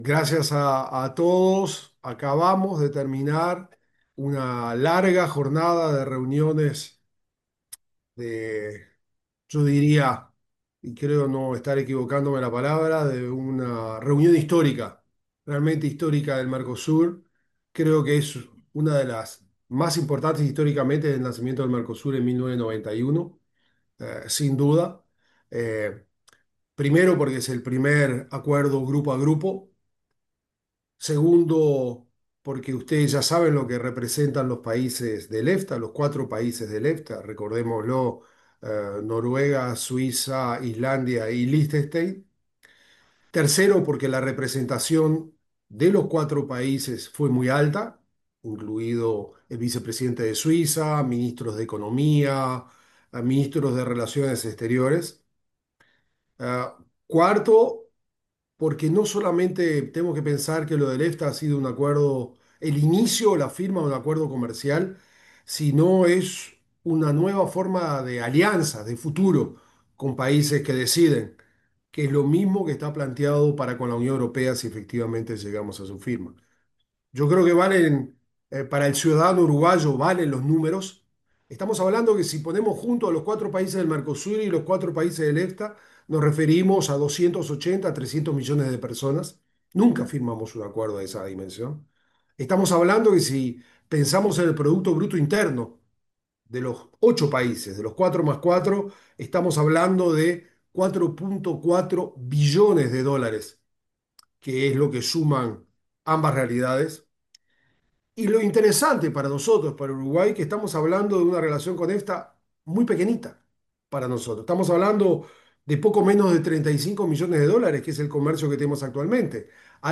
Declaraciones del canciller Mario Lubetkin 17/09/2025 Compartir Facebook X Copiar enlace WhatsApp LinkedIn El ministro de Relaciones Exteriores, Mario Lubetkin, se expresó en una conferencia de prensa tras la firma de un tratado de libre comercio entre el Mercosur y la Asociación Europea de Libre Comercio.